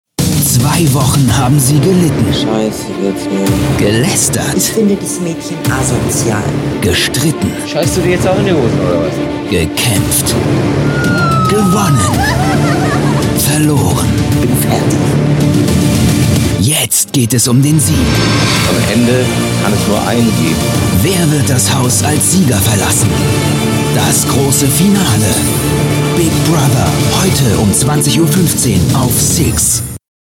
Manfred Lehmann (Berlin) spricht Sixx Manfred Lehmann ist Bruce Willis - "jipijeijeh Schweinebacke".